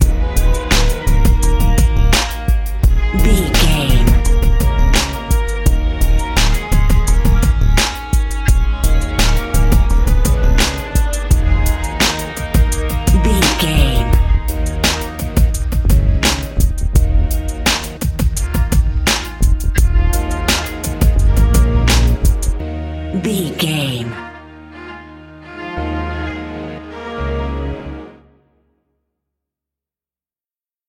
Epic / Action
Aeolian/Minor
E♭
drum machine
synthesiser
funky